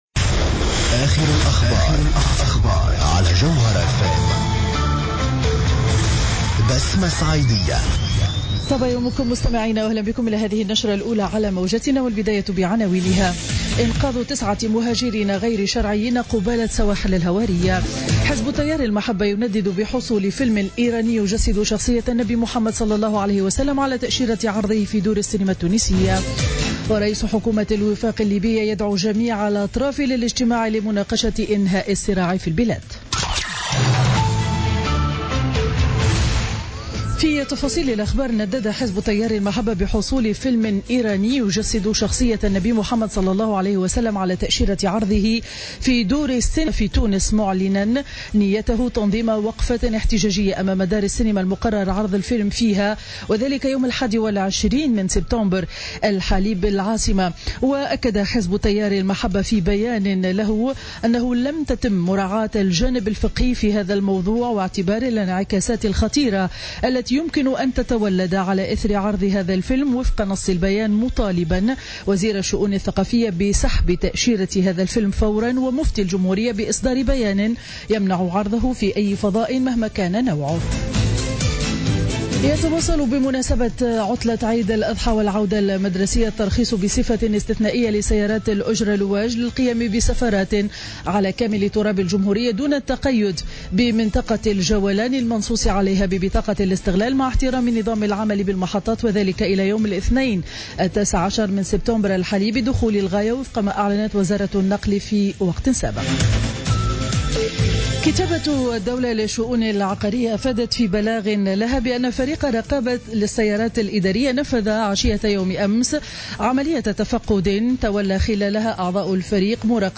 نشرة أخبار السابعة صباحا ليوم الأربعاء 14 سبتمبر 2016